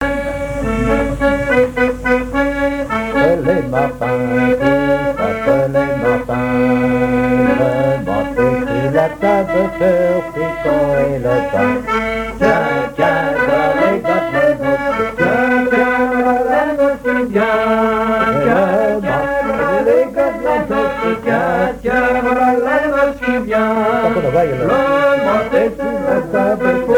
Genre laisse
Enquête Tap Dou Païe et Sounurs, sections d'Arexcpo en Vendée
Pièce musicale inédite